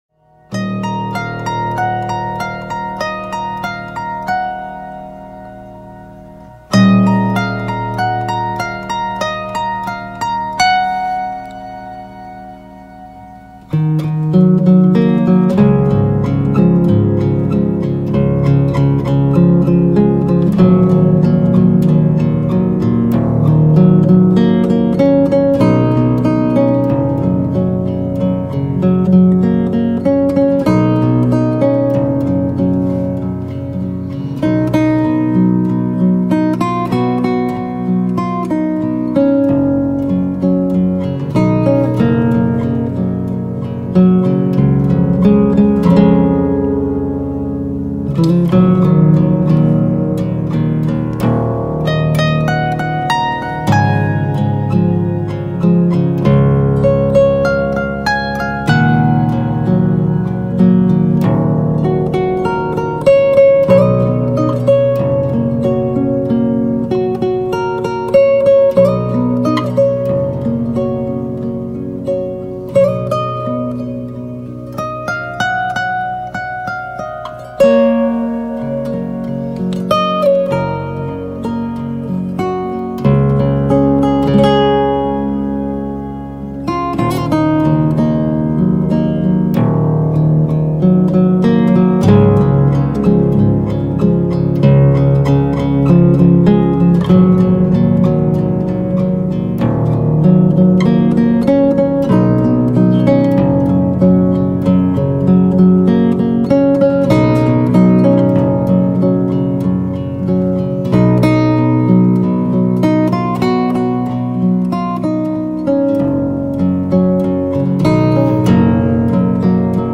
جلوه های صوتی
صدای گیتار بی کلام